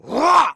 client / bin / pack / Sound / sound / monster / thief1 / attack_1.wav
attack_1.wav